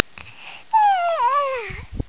SYawn.wav